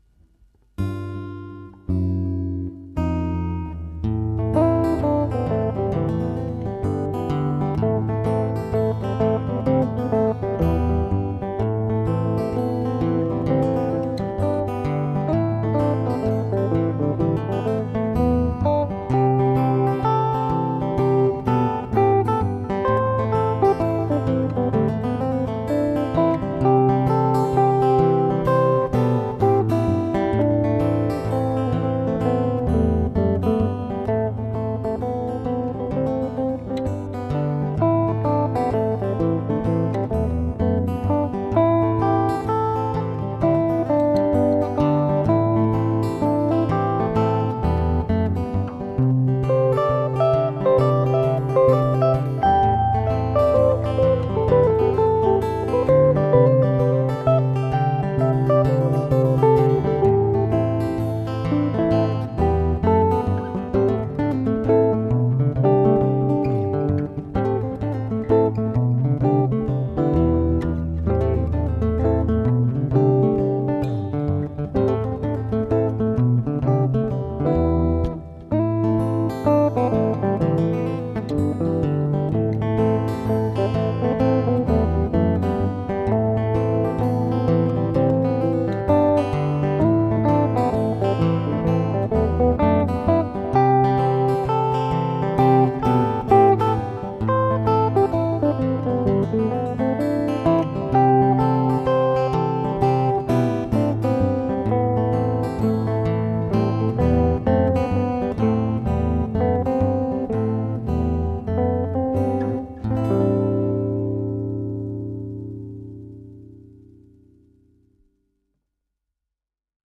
Gitarren